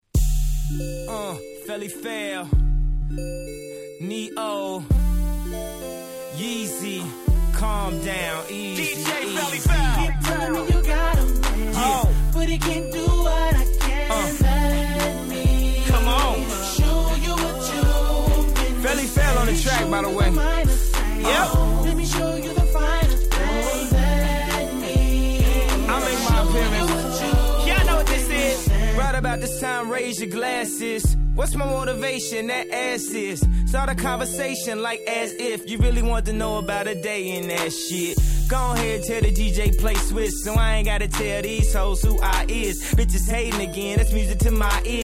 08' Big Hit R&B !!